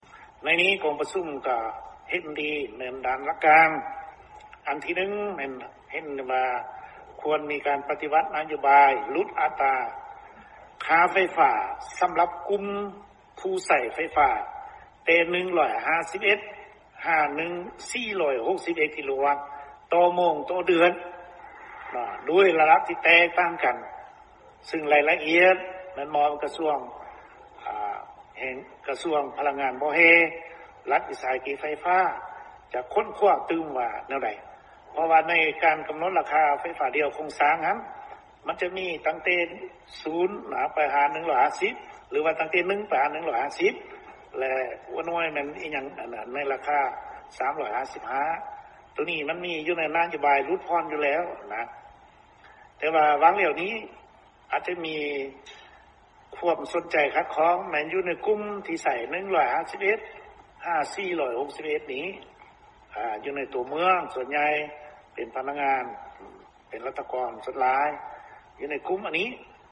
ເຊີນຟັງລາຍງານ ລາຄ່າໄຟຟ້າ